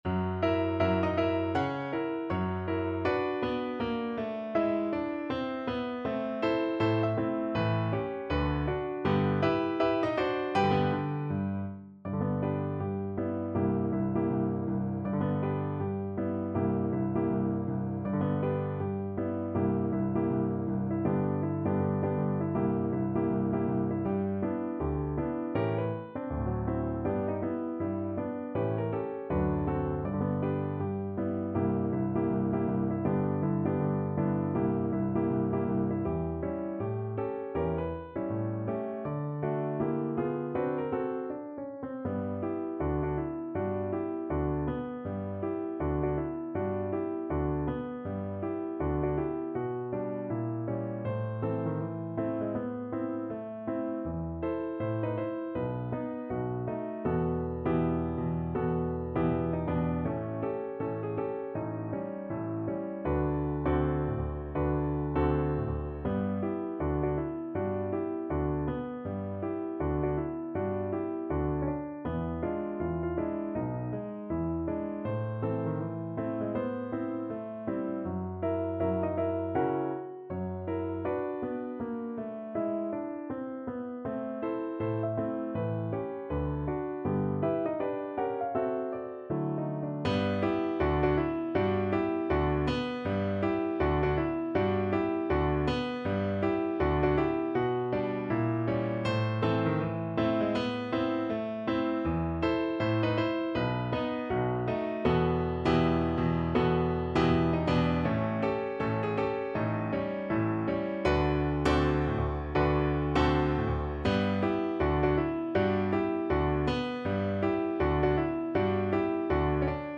~ = 160 Moderato
Jazz (View more Jazz Voice Music)